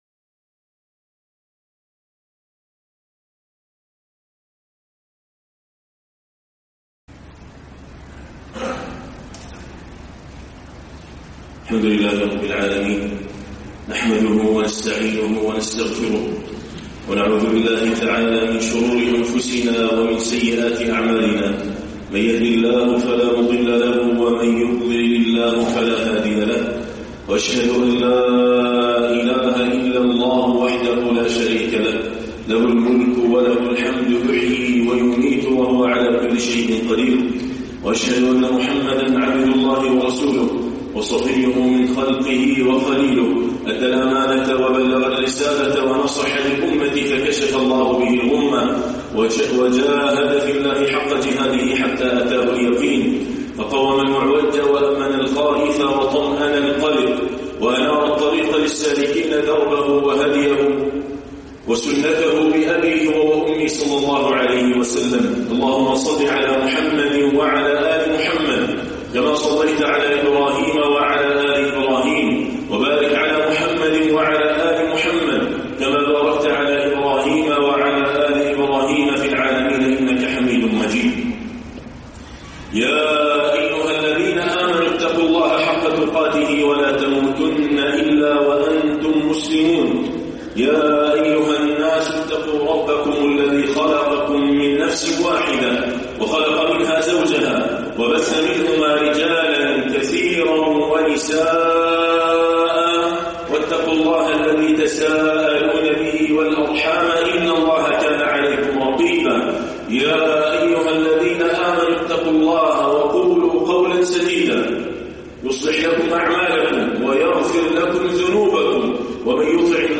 من فقه شهر شعبان - خطبة